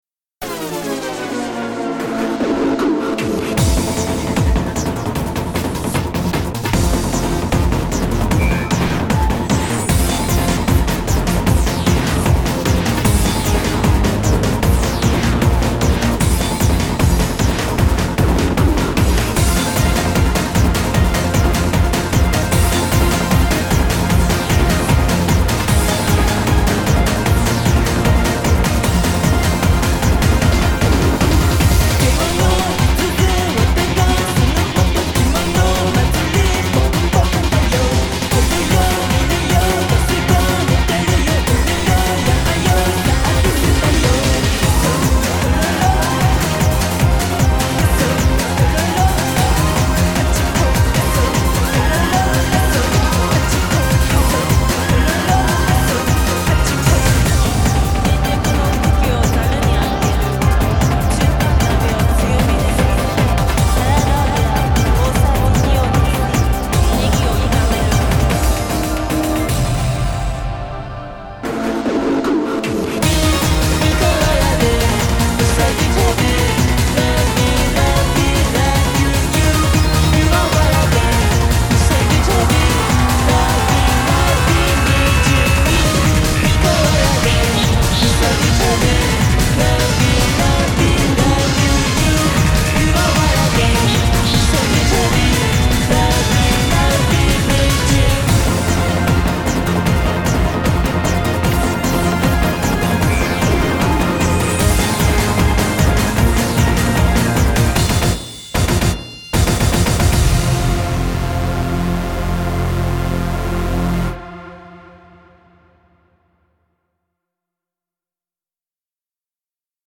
BPM304-608
Audio QualityPerfect (High Quality)
Despite the title, it actually never reaches 300 BPM...